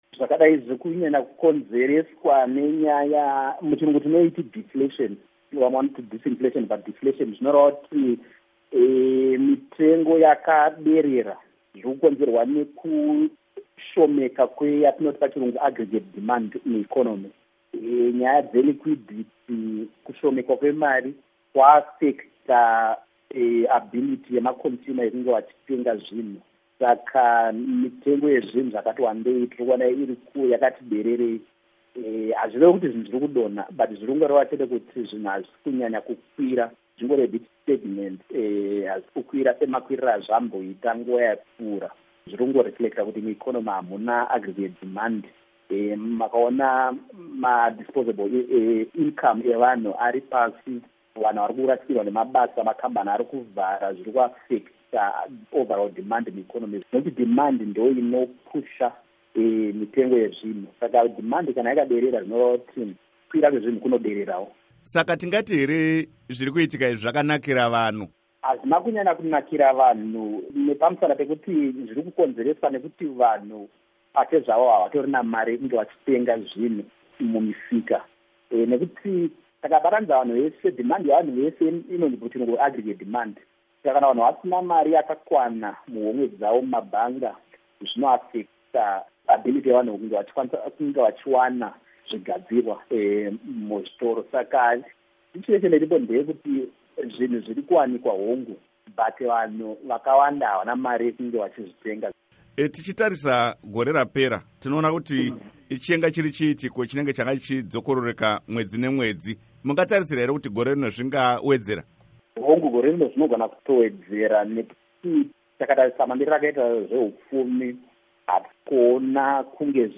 Hurukuro